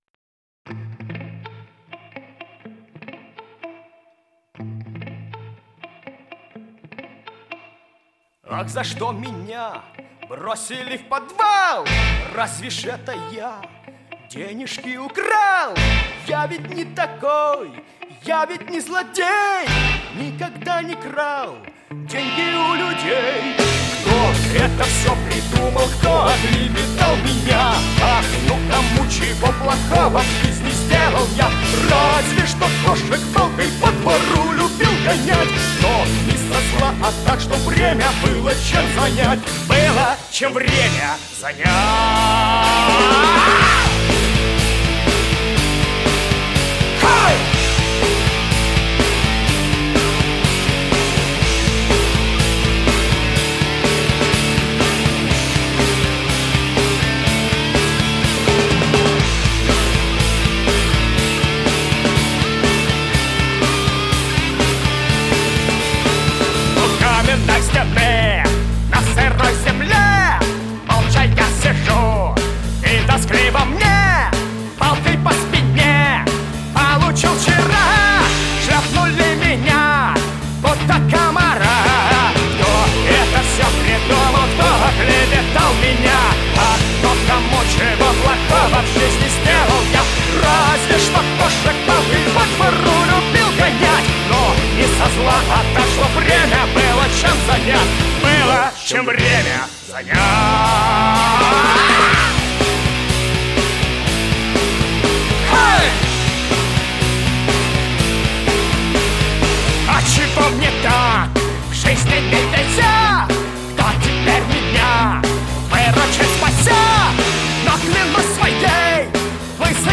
Рок
Жанр: Жанры / Рок